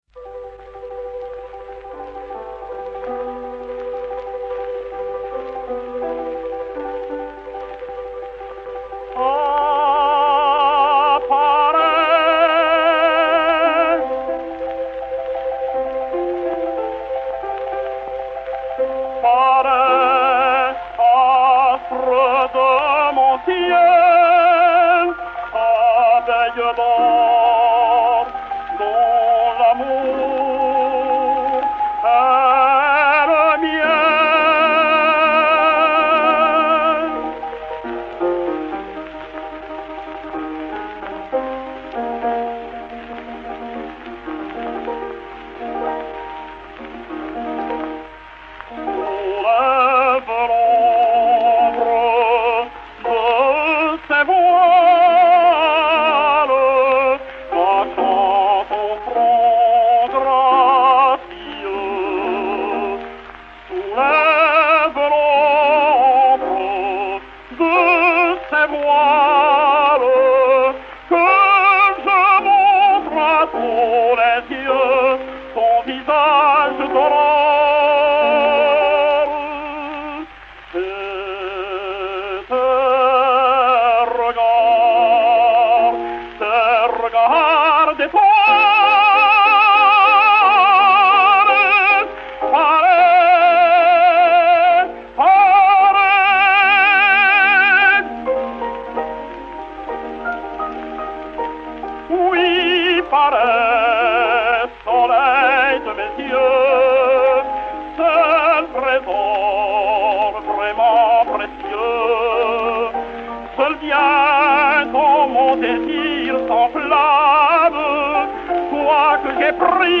Léonce Escalaïs (Zarâstra) et Piano
XPh 442, enr. à Milan le 10 novembre 1905